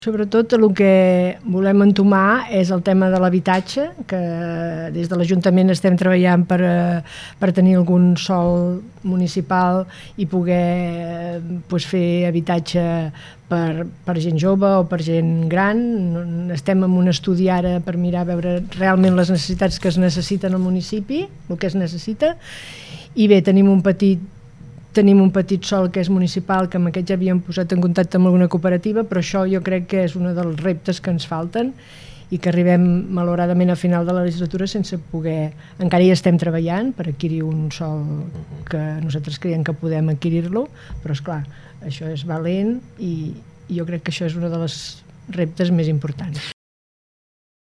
Aquest és només un dels temes d’actualitat que hem tractat amb l’alcaldessa, que ha passat per l’estudi de Ràdio Capital.